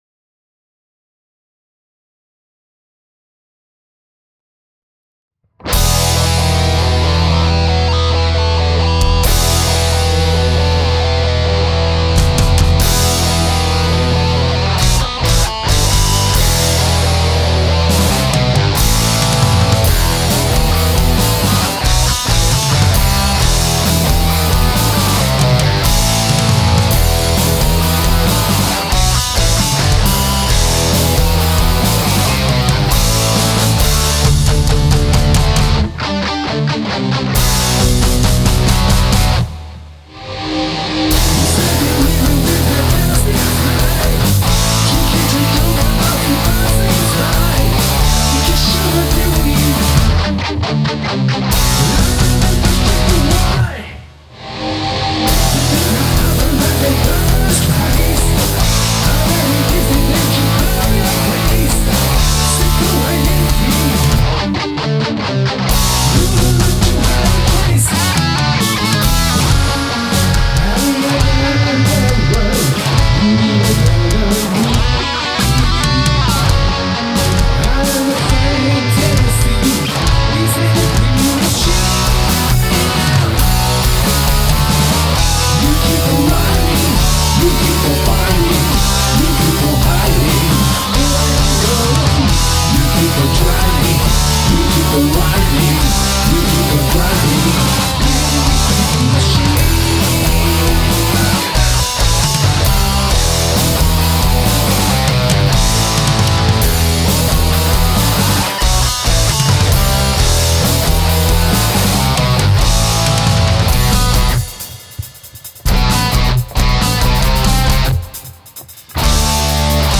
これはもう自分の技術のはるか上を行くドラムなわけで
ボーカルは色っぽい声のゲストさんです。
GuestVocal
Guitar & Bass
Drums